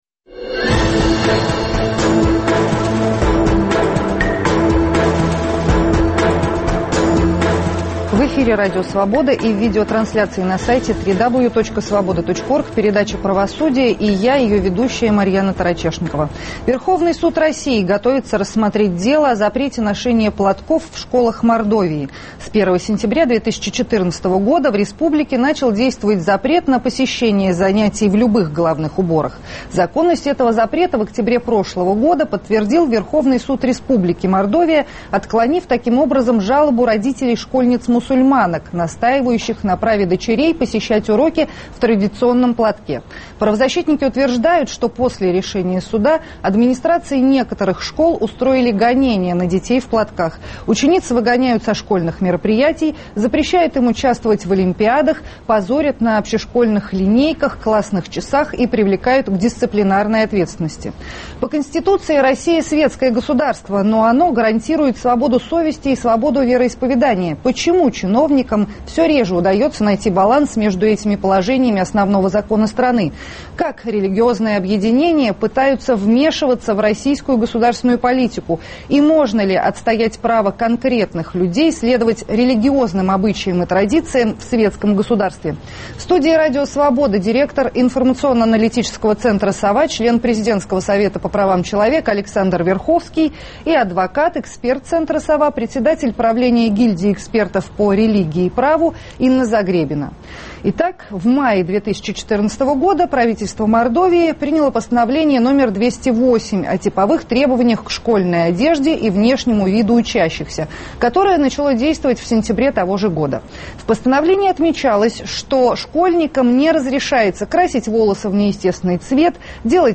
В студии Радио Свобода